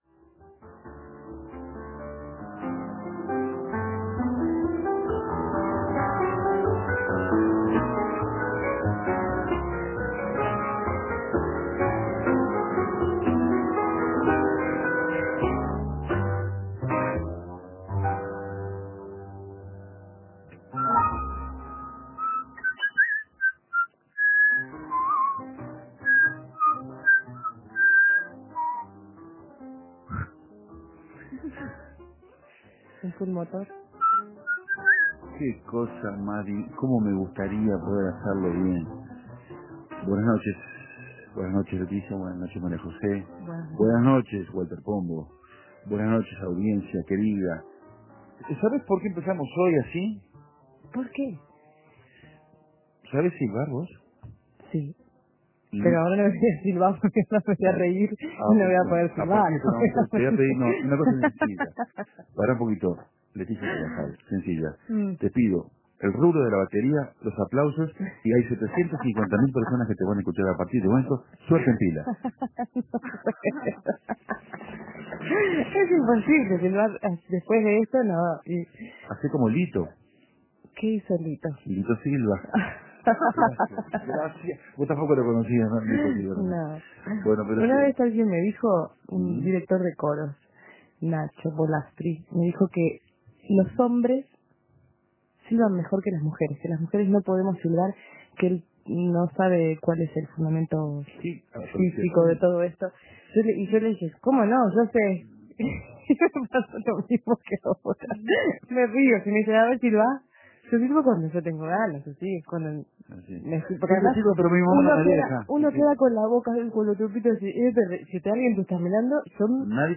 Hablando de tango, propusimos un concurso de silbidos.